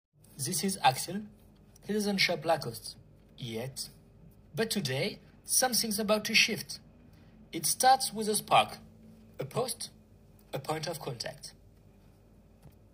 20 - 35 ans - Ténor